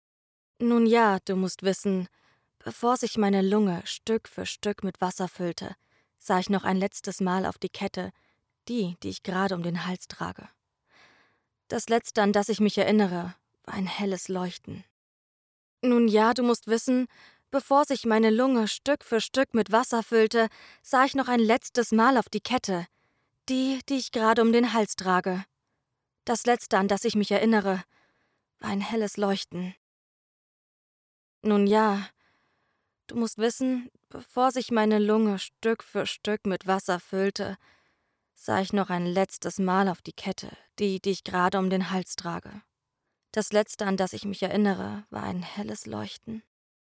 Hier ist mein Probetake 🙂 Vielen Dank!